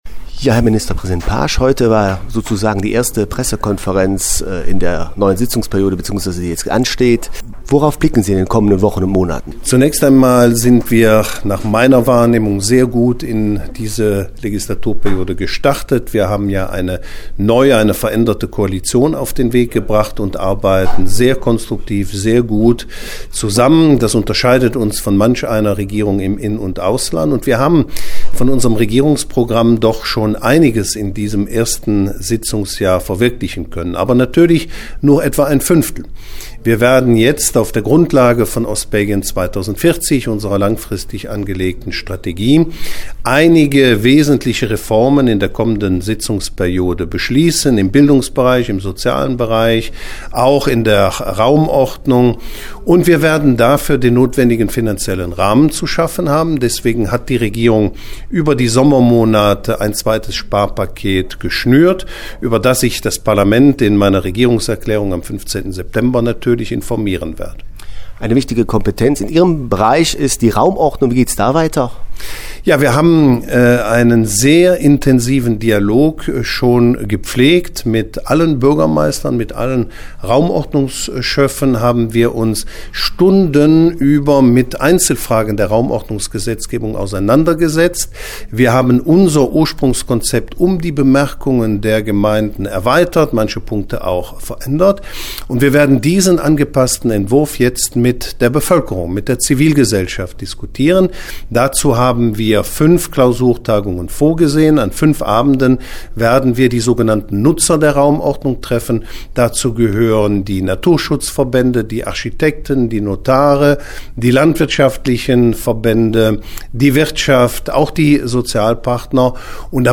hat mit Ministerpräsident Oliver Paasch gesprochen